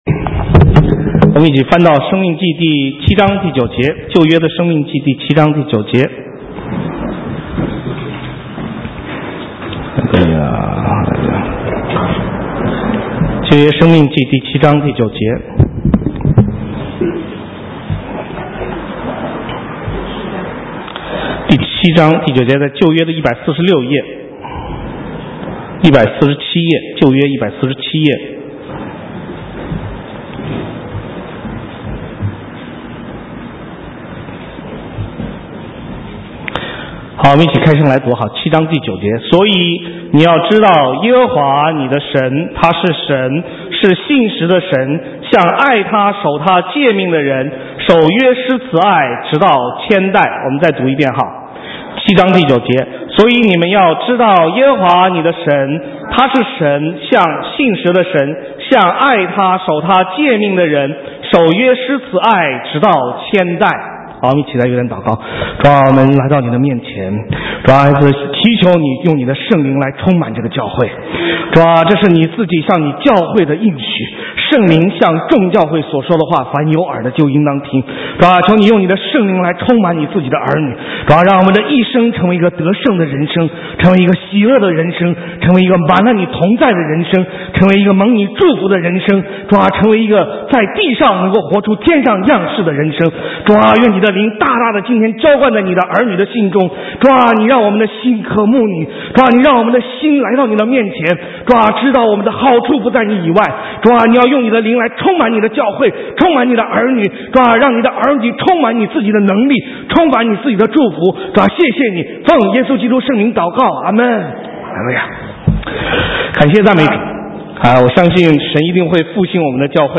神州宣教--讲道录音 浏览：约的更新 (2011-07-24)